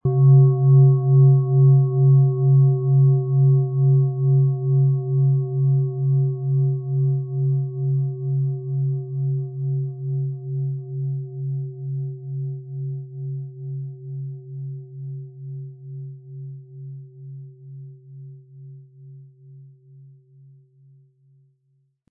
• Mittlerer Ton: Biorhythmus Geist
• Höchster Ton: Wasser
PlanetentöneOM Ton & Biorhythmus Geist & Wasser (Höchster Ton)
HerstellungIn Handarbeit getrieben
MaterialBronze